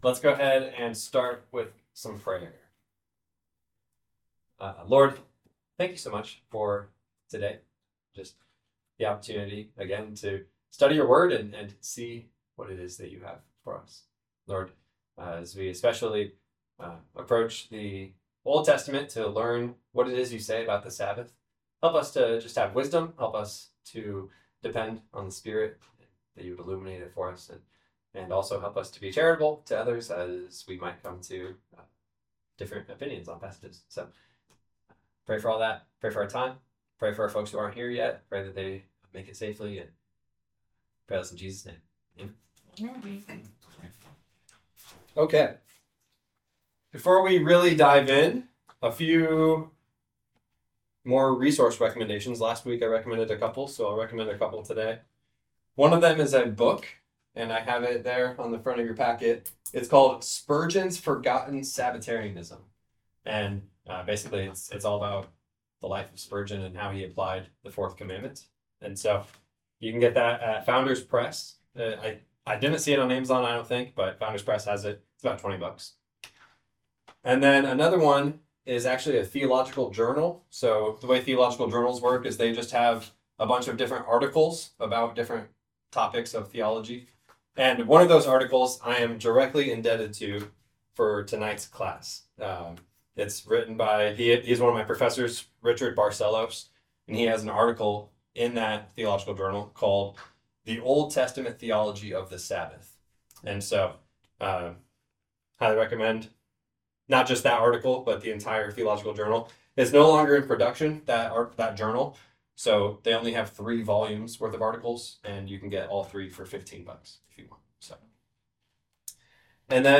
Meaning the audio is recorded from a mic that picks up the whole room and has only received a minimum amount of editing afterwards.